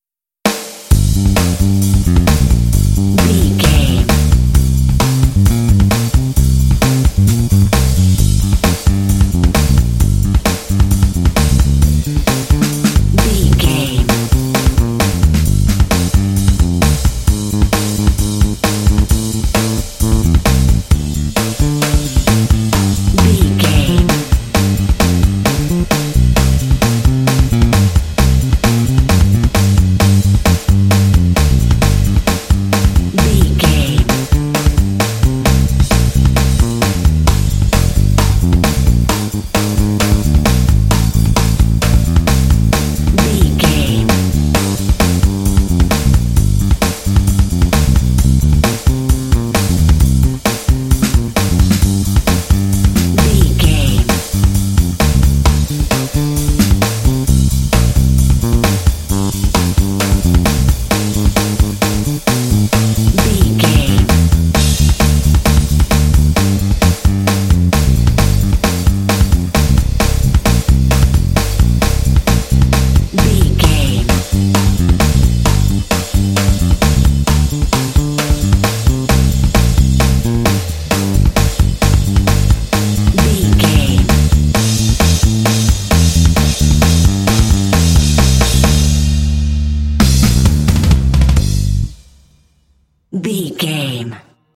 This bluesy track is full of urban energy.
Aeolian/Minor
funky
groovy
energetic
driving
bass guitar
drums
blues
jazz